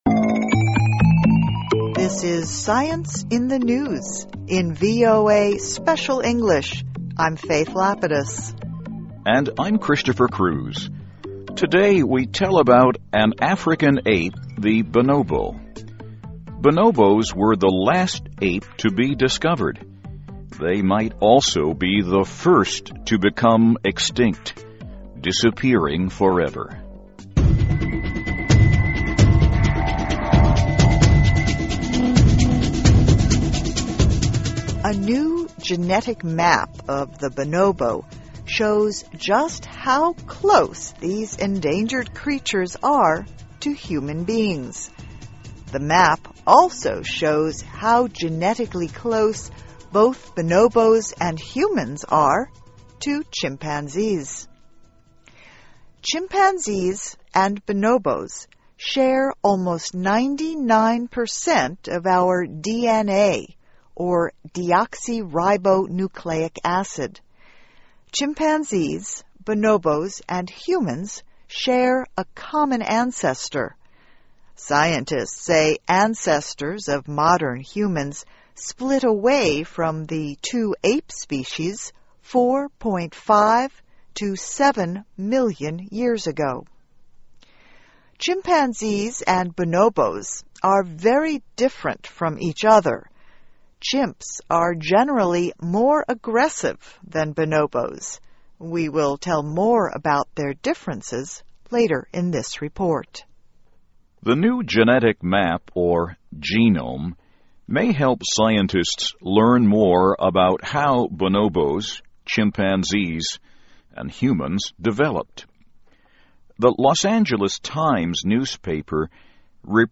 This is SCIENCE IN THE NEWS, in VOA Special English.